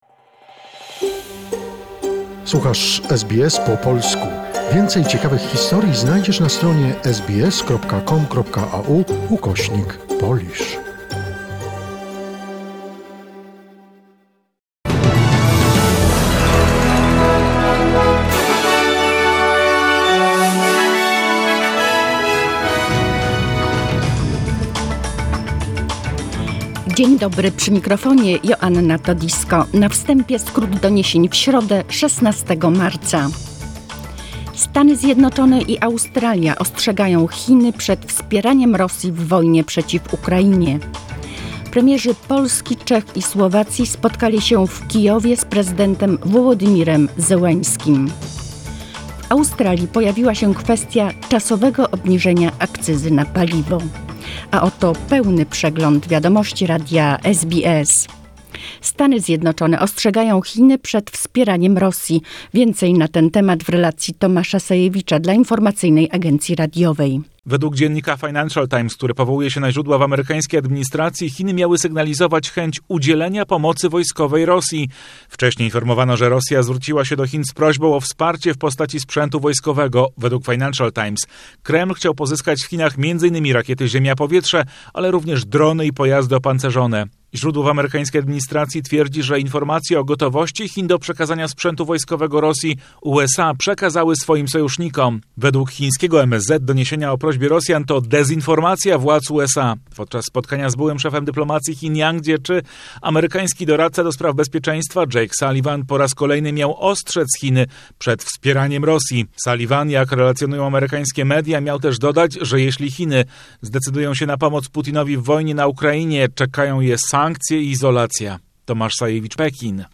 SBS News in Polish, 16 March 2022